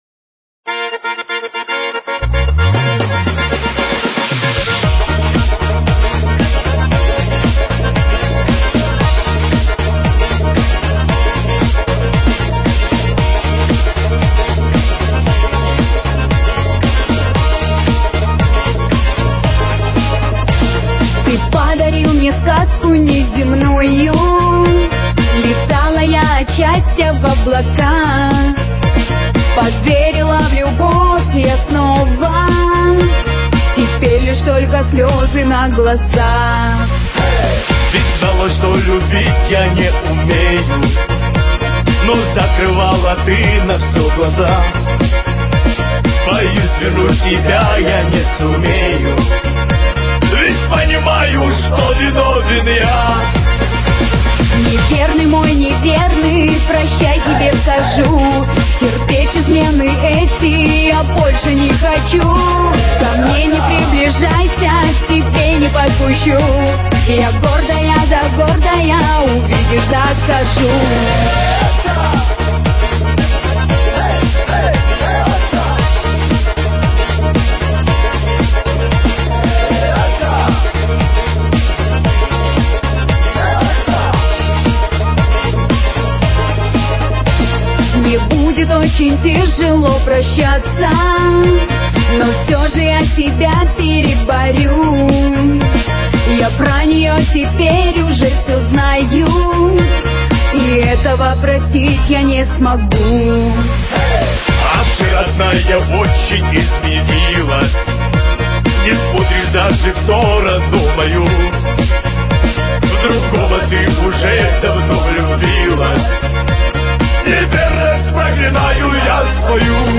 Назад в (поп)...
попса